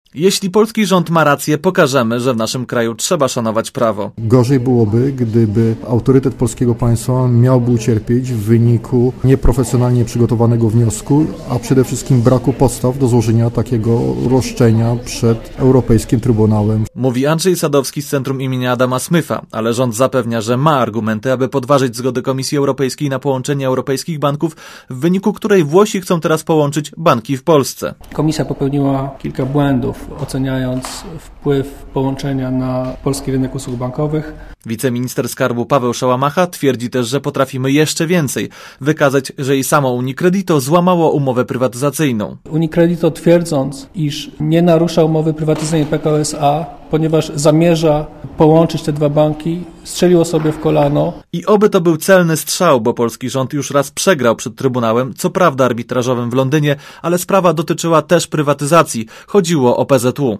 Źródło zdjęć: © Archiwum 06.02.2006 18:46 ZAPISZ UDOSTĘPNIJ SKOMENTUJ Relacja reportera Radia ZET